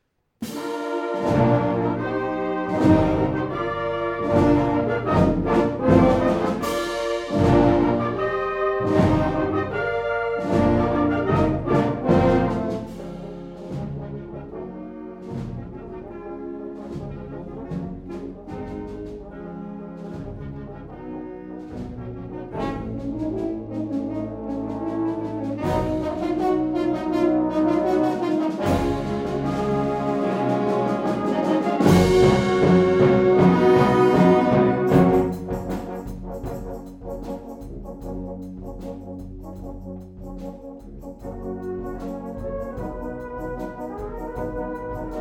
Konzertaufnahme mit kleinem Equiqment
Also zu meinem Problem: Ich möchte gerne eine Aufnahme von einem Konzert einer Musikkapelle machen mit ca 40 Leuten.
Da leider nicht viel Zeit war, habe ich nur die 2 Großmembran ca 3,5 Meter vor dem Orchester in ca 4m Höhe montiert.
Für mich kling die Aufnahme sehr dumpf und an manchen Stellen viel zu leise.
ich habe den bereich um die 440hz als extrem dick empfunden.